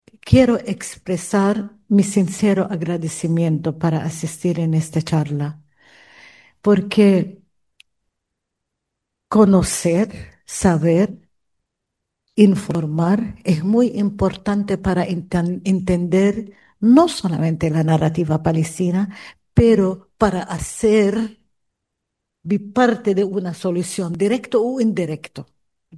La embajadora de Palestina en Chile, Vera Baboun, fue la expositora invitada a la charla “Palestina hoy: una mirada desde los Derechos Humanos”, organizada por el Equipo de Litigación Internacional en Derechos Humanos de la Universidad de Concepción.